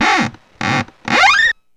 Index of /90_sSampleCDs/E-MU Producer Series Vol. 3 – Hollywood Sound Effects/Human & Animal/WoodscrewSqueaks
WOOD SQUEA05.wav